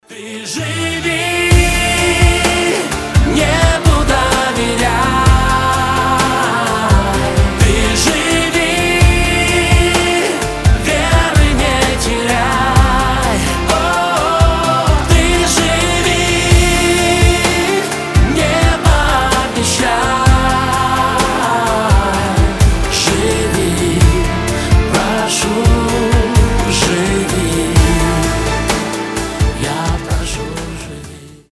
• Качество: 320, Stereo
душевные
красивый мужской голос
эстрадные